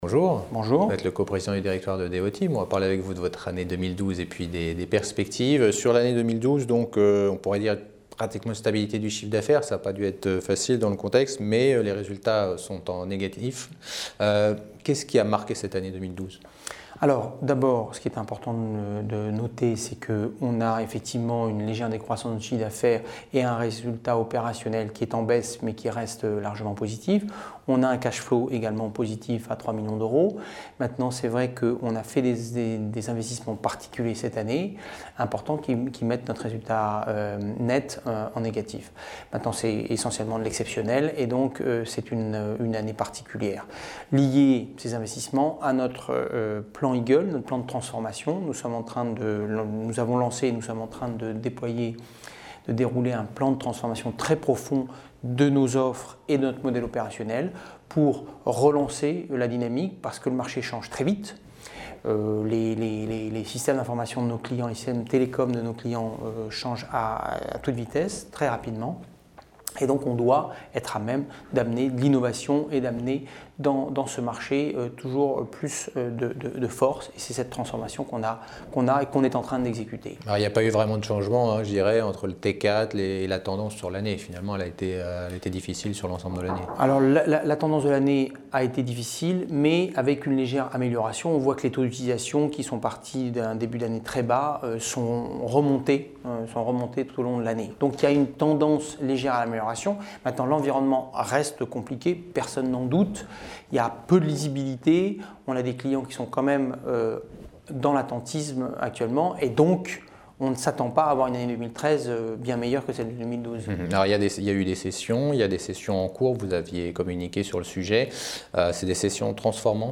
Résultats annuels 2012 : Interview